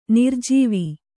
♪ nirjīvi